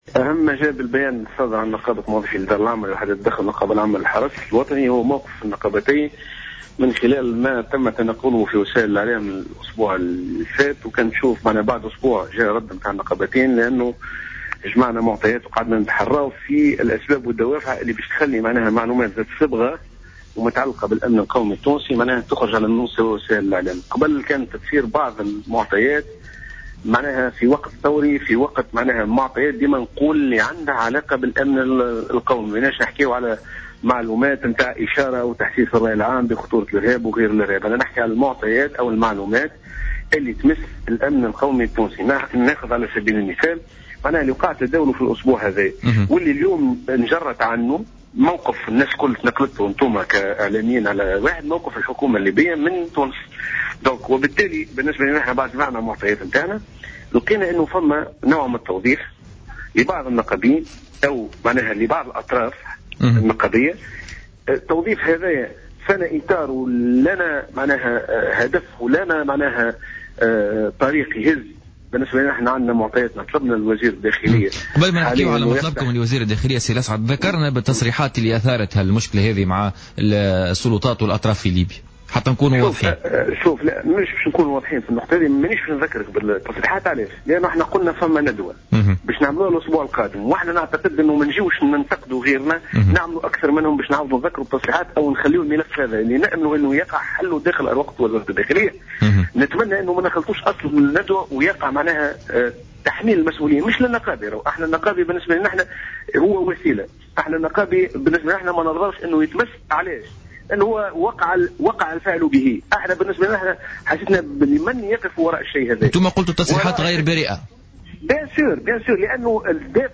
في مداخلة له في برنامج بوليتيكا